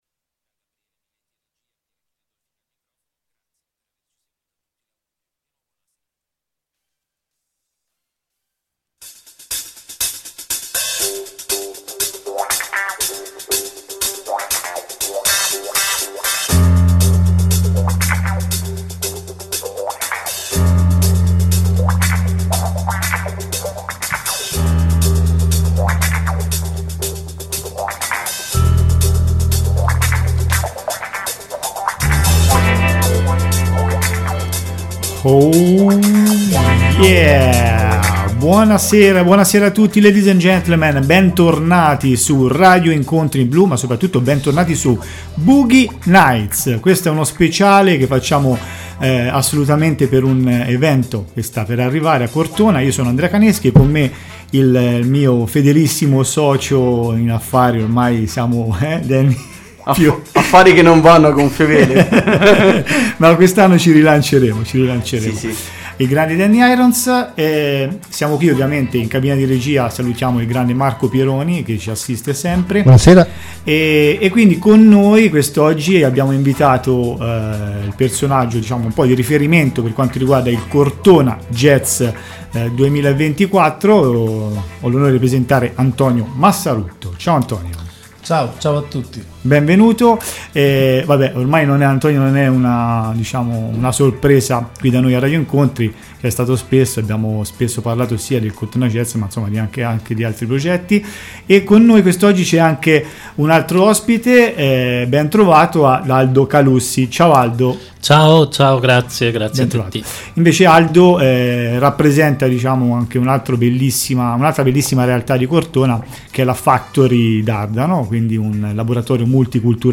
Speciale - Cortona Jazz 2024 dallo Studio 1